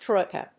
troi・ka /trˈɔɪkə/
• / trˈɔɪkə(米国英語)